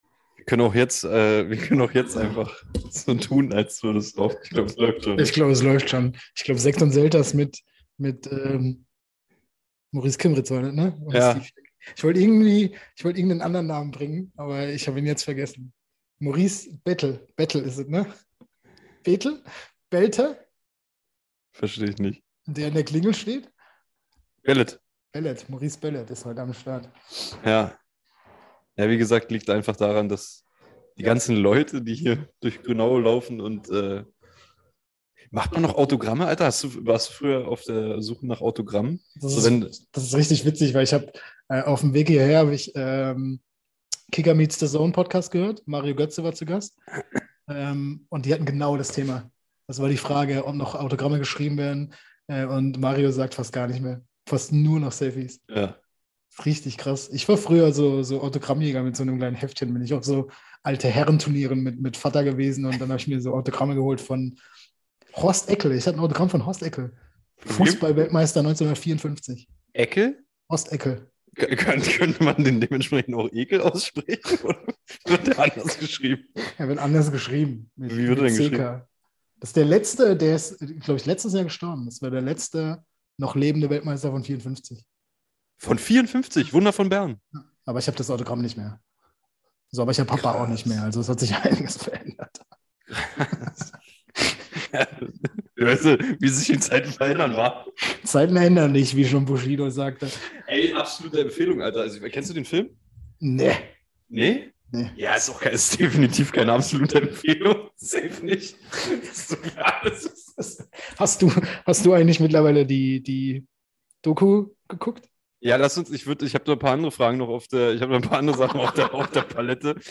Für die von uns ungewohnt nicht profihafte Tonqualität bitten wir um Entschuldigung und danken für Euer Verständnis, Ihr positiv Fokussiert:innen :-*